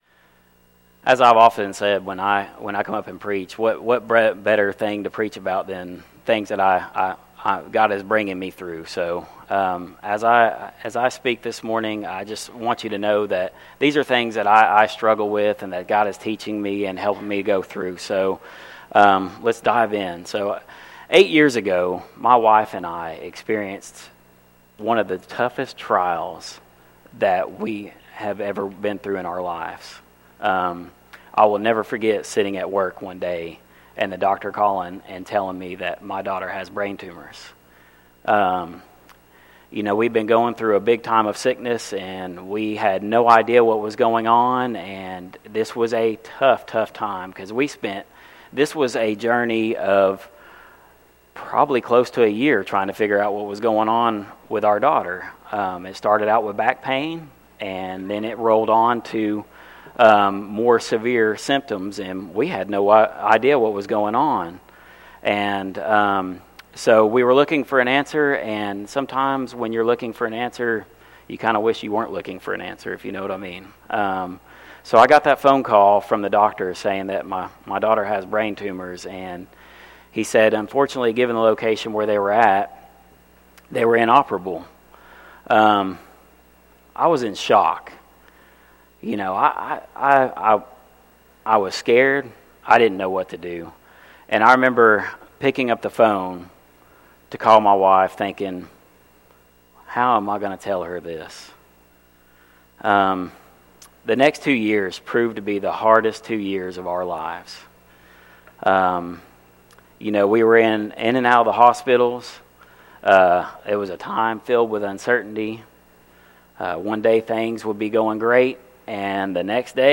Messages by pulpit guests and special occasion sermons by pastor